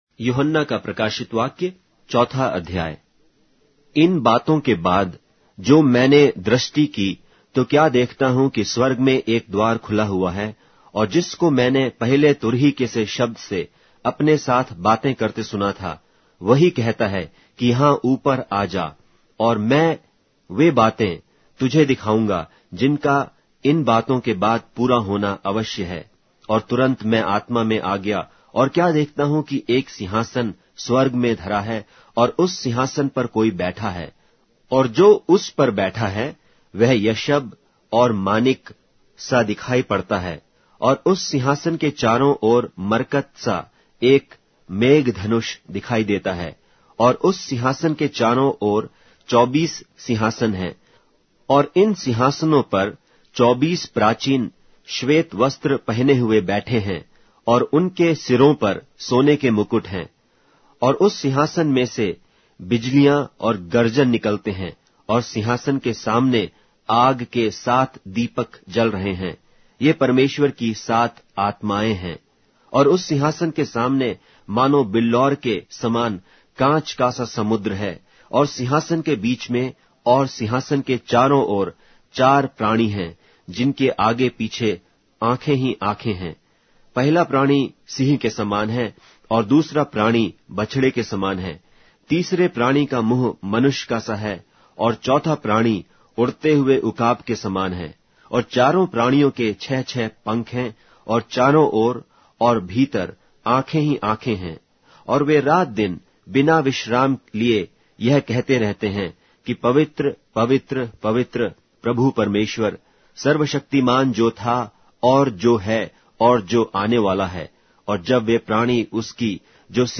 Hindi Audio Bible - Revelation 8 in Mrv bible version